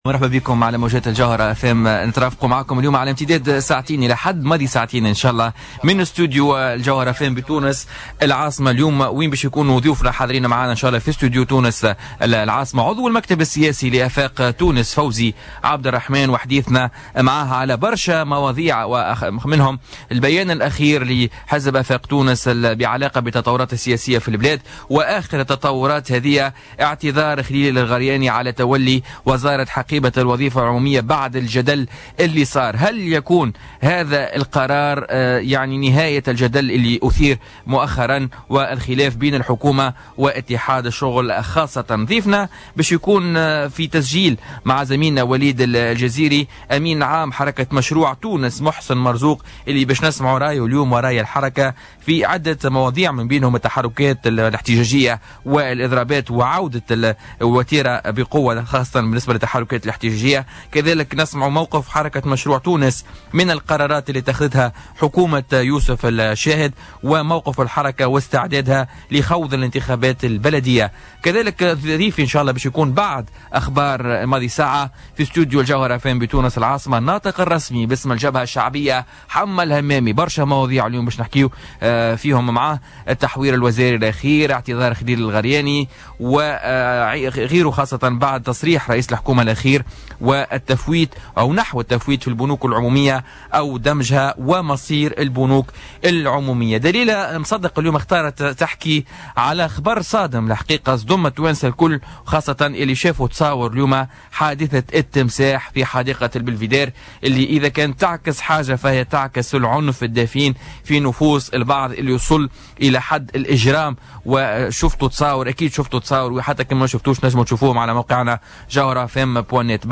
السياسي حمة الحمامي و فوزي عبد الرحمان الأمين العام لحزب آفاق تونس ضيوف بوليتيكا